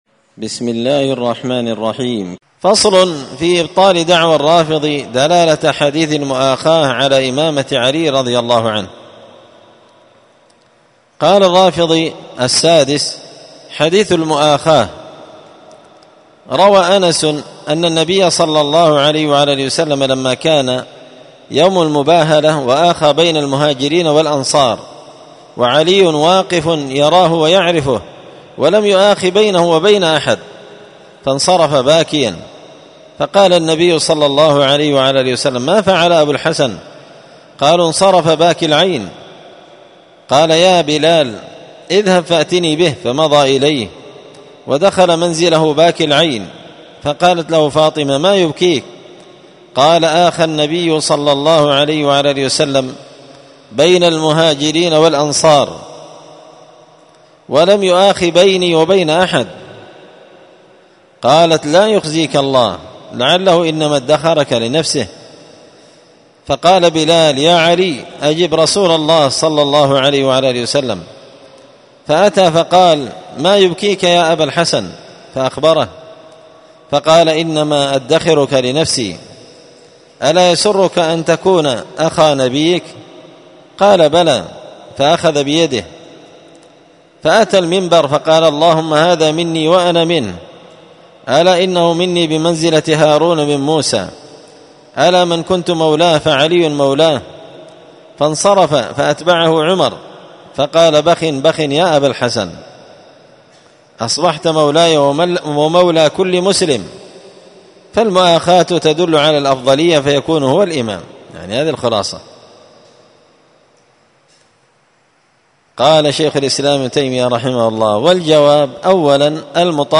الدرس السادس والتسعون بعد المائة (196) فصل في إبطال دعوى الرافضي دلالة حديث المؤاخاة على إمامة علي
مسجد الفرقان قشن_المهرة_اليمن